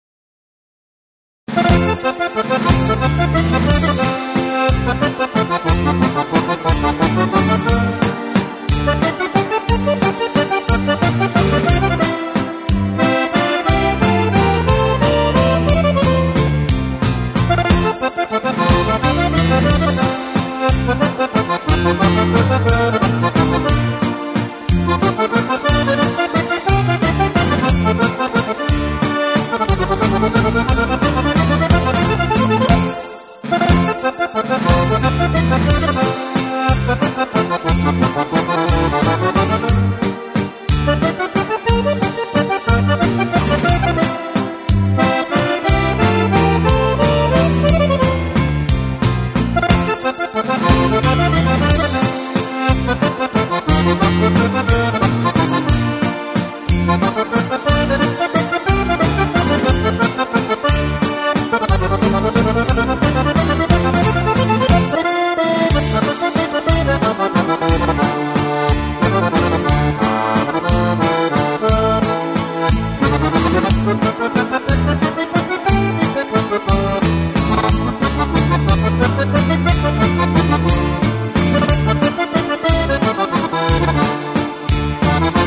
Playback + Akkordeon noten